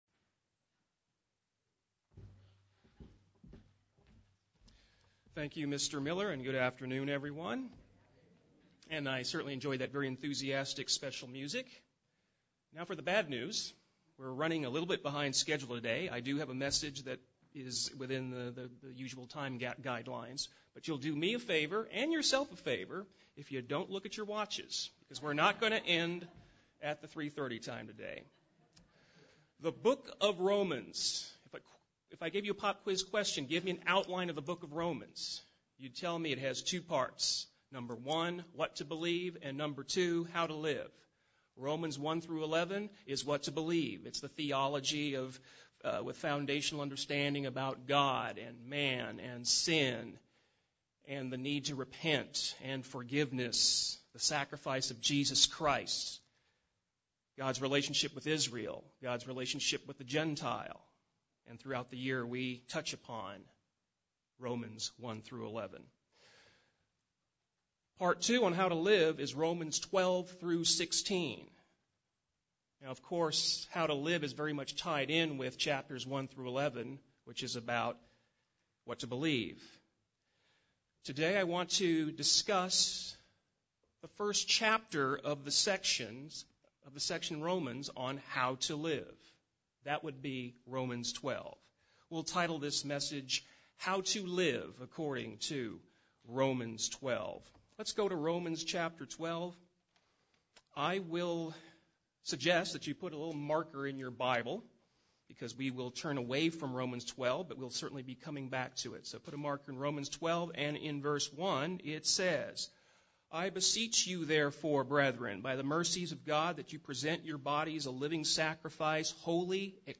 Given in San Diego, CA
UCG Sermon Studying the bible?